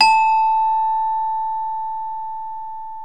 CELESTE A3.wav